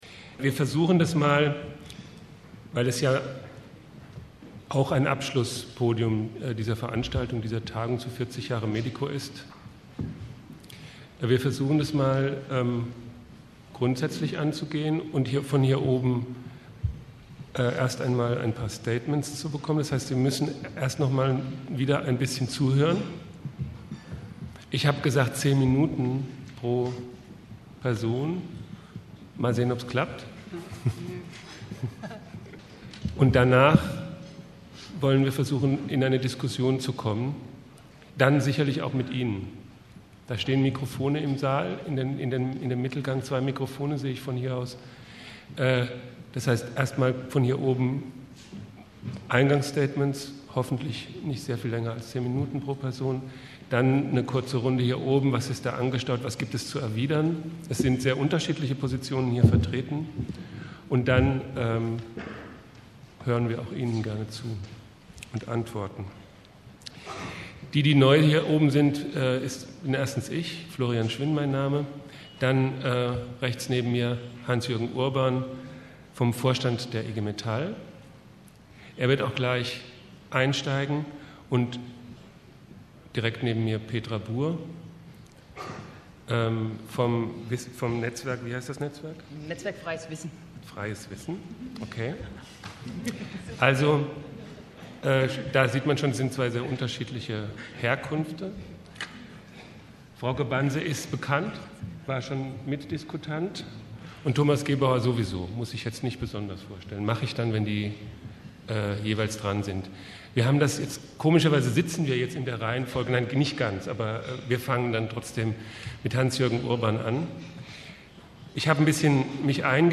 abschlusspodium.mp3